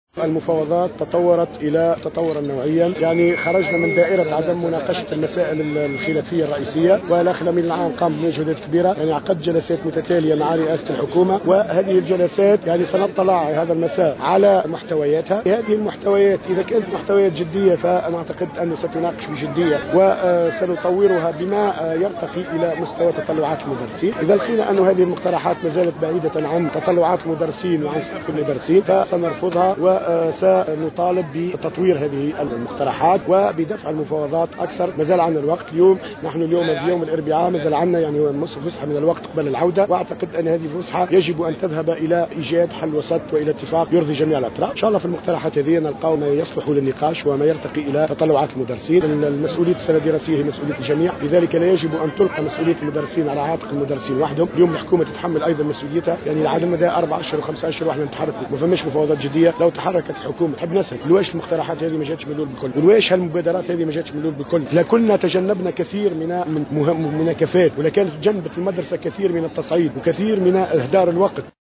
في تصريح للجوهرة أف أم على هامش يوم غضب الأساتذة الذي دعت إليه جامعة الثانوي